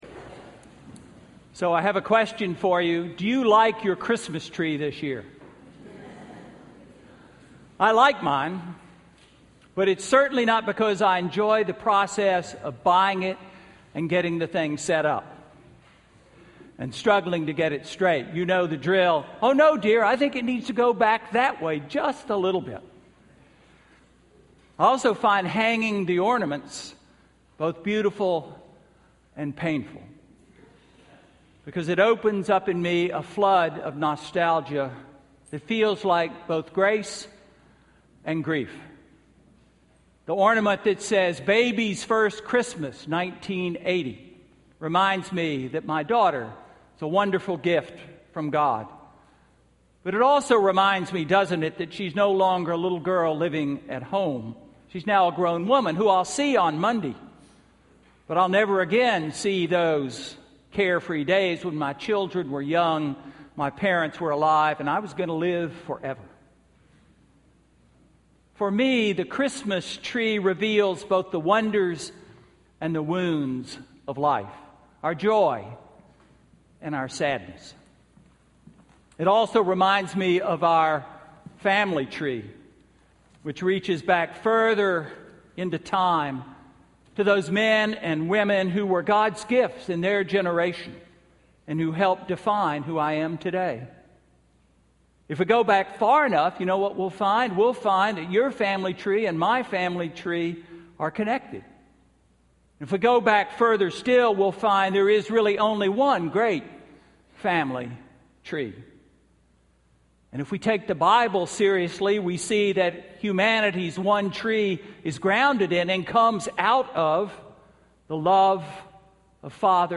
Sermon–Christmas Eve